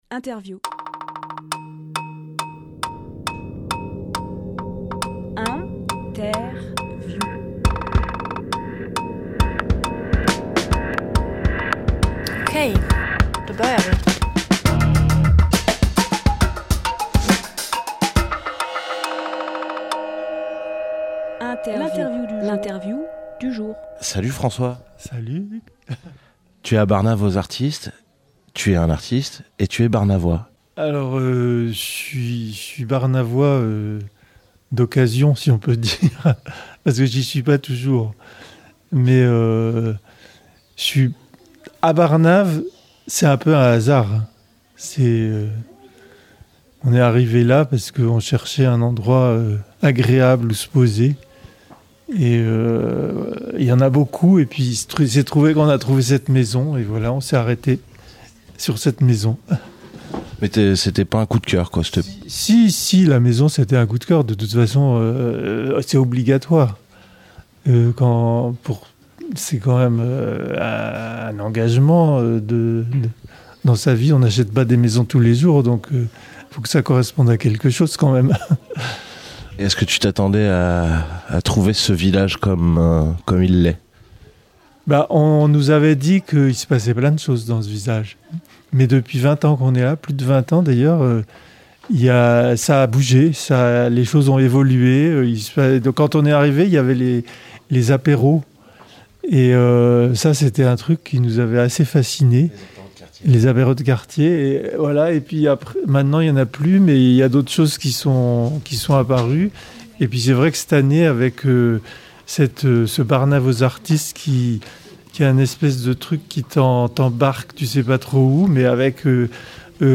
Emission - Interview Barnave Aux Artistes